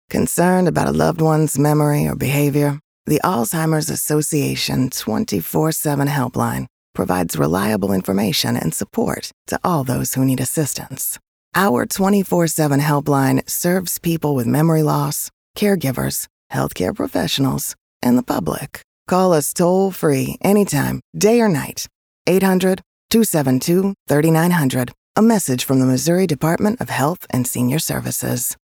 Radio Spot 1 — MO DHSS
Radio-Spot-1-MO-DHSS.mp3